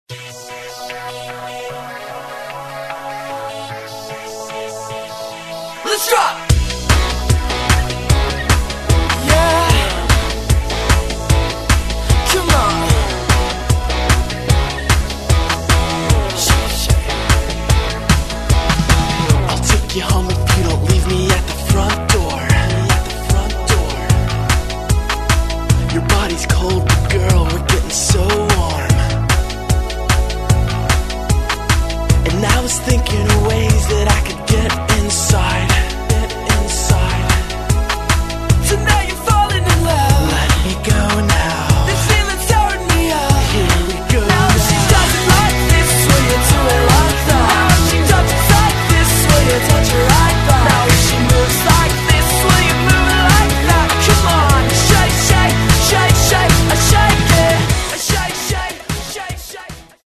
W niezwykle udany sposób łączy pop z echami punka.